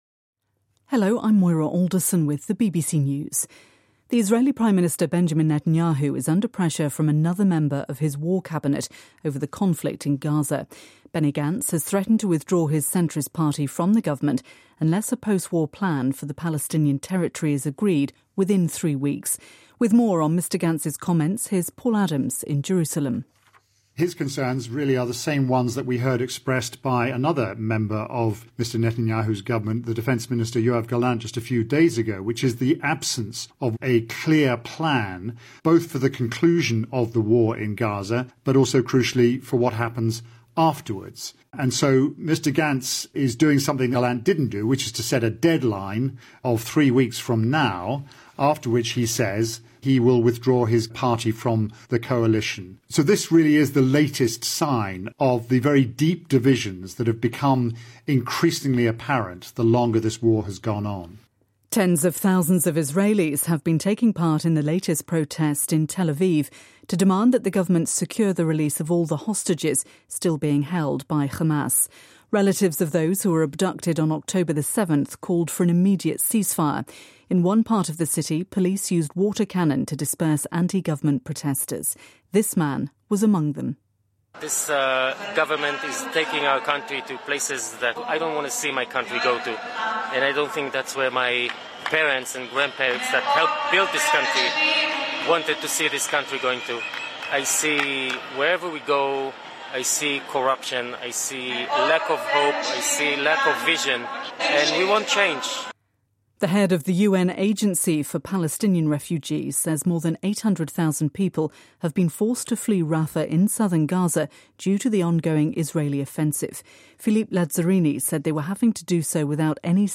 BBC新闻